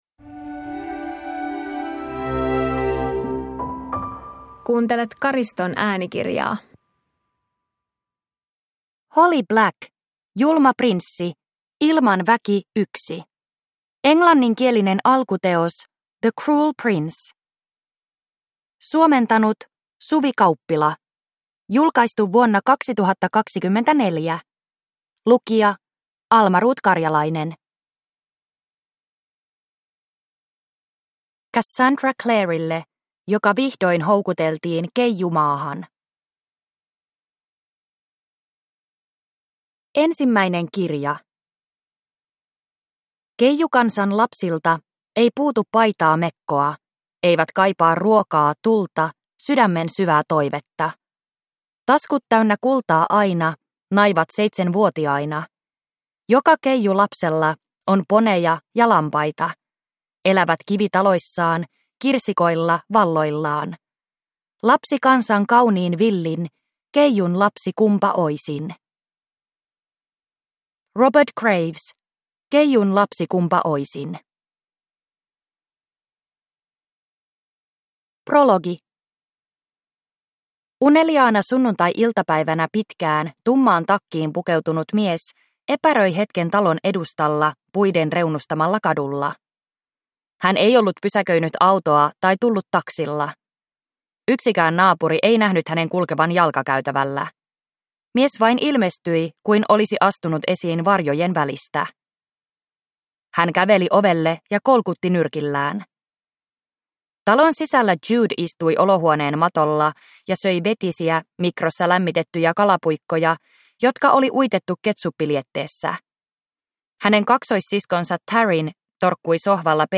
Julma prinssi – Ljudbok